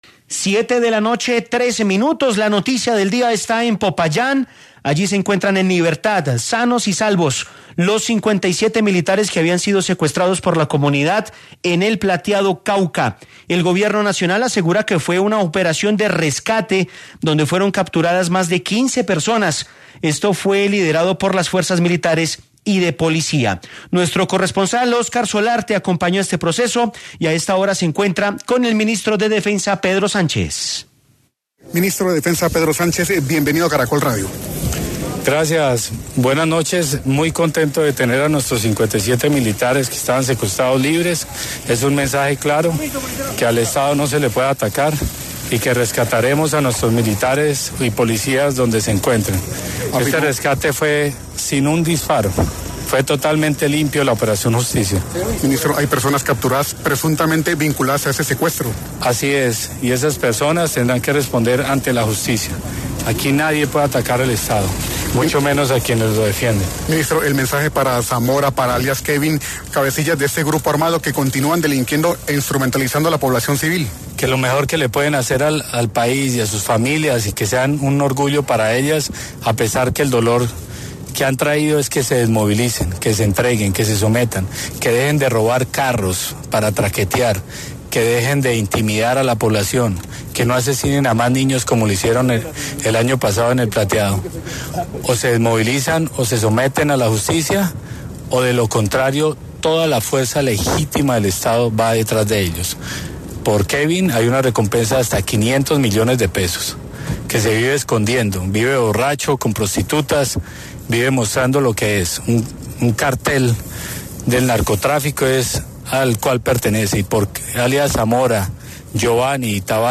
En entrevista con Caracol Radio, el jefe de cartera aseguró que con esta operación se ha dejado “un mensaje claro: al Estado no se le puede atacar. Rescataremos a nuestros militares y policías donde se encuentren”.